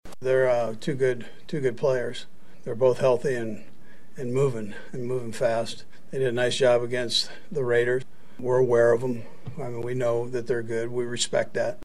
Chiefs head coach Andy Reid on Khalil Mack and Joey Bosa leading the Chargers defense.